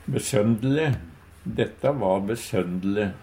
DIALEKTORD PÅ NORMERT NORSK besøndele besynderleg Ubunde han-/hokj.